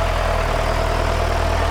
stardew-valley-mods/TractorMod/assets/audio/rev.ogg at main
rev.ogg